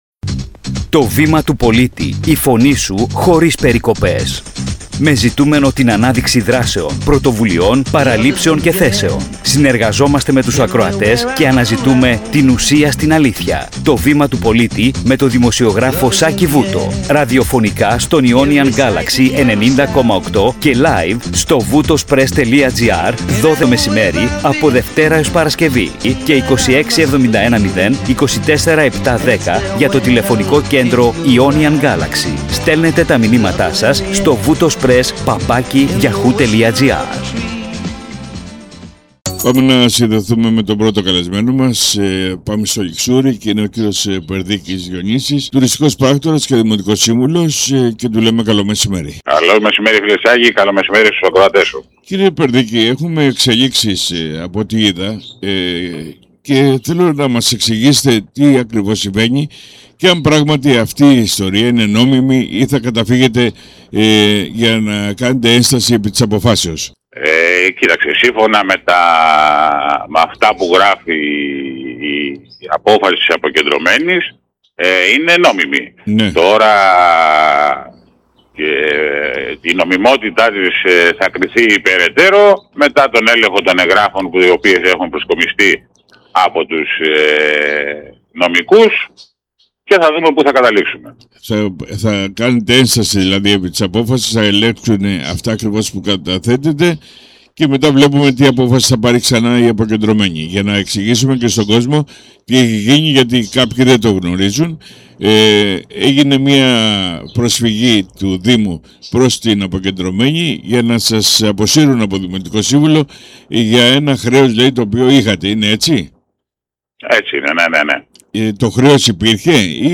Ακούστε ολόκληρη τη συνέντευξη. audioart Στην εκπομπή «Το Βήμα του Πολίτη» φιλοξενήθηκε ο τουριστικός πράκτορας και δημοτικός σύμβουλος Ληξουρίου Διονύσης Περδίκης,